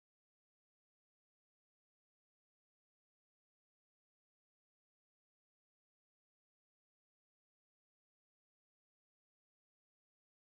English rock band
bass guitarist